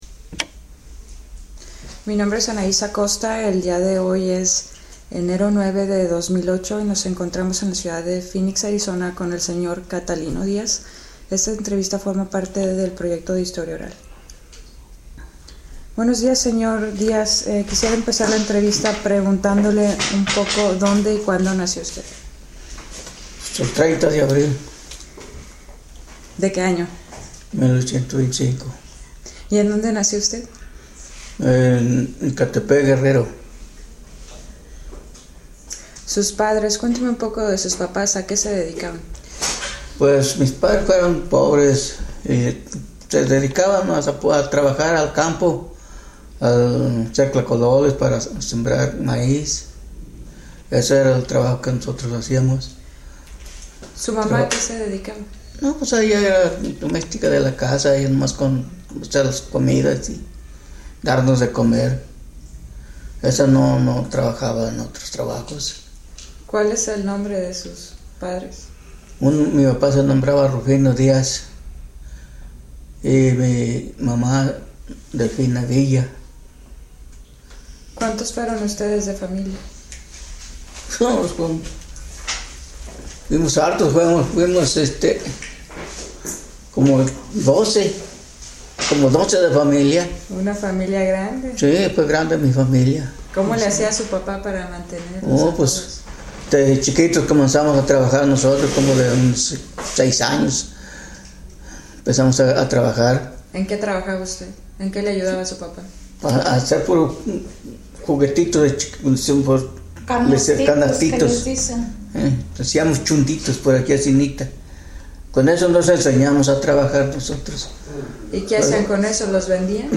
Location Phoenix, Arizona